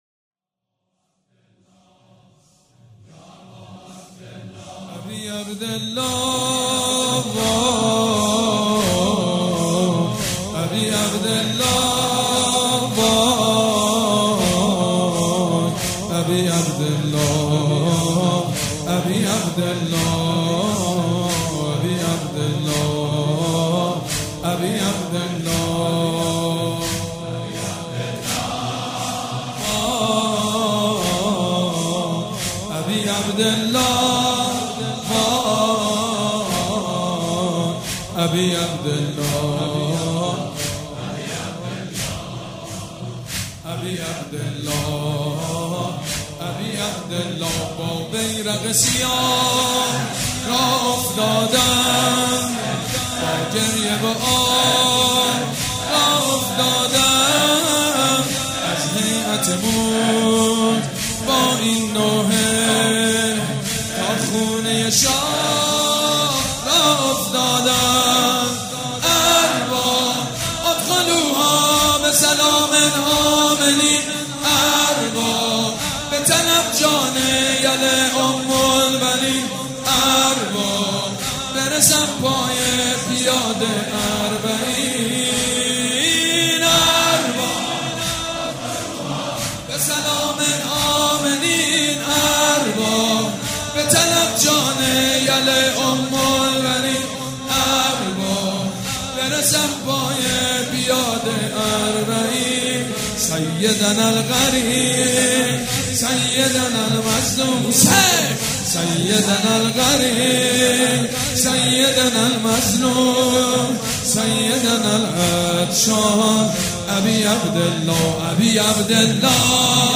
شب هفتم محرم95/هیئت ریحانه الحسین(س)